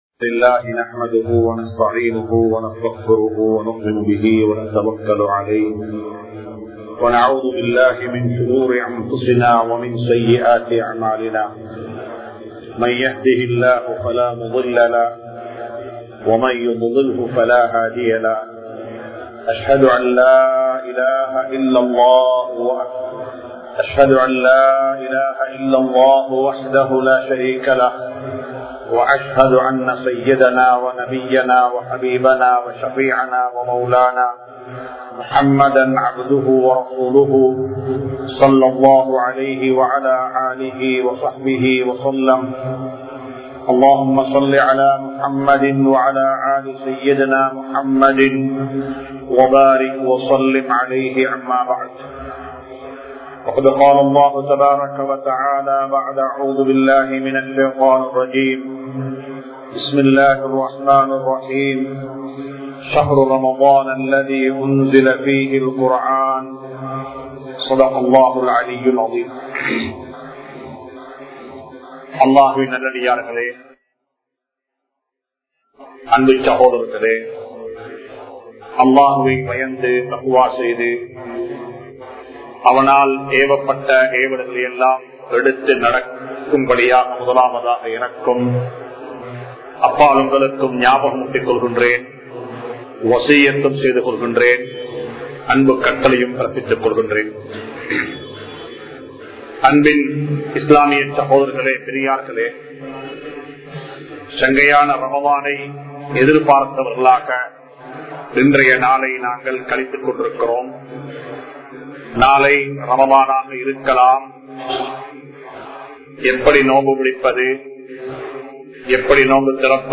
Ramalanil Mun Maathiriyaaha Vaala Palahuvoam (ரமழானில் முன்மாதிரியாக வாழ பழகுவோம்) | Audio Bayans | All Ceylon Muslim Youth Community | Addalaichenai
Majma Ul Khairah Jumua Masjith (Nimal Road)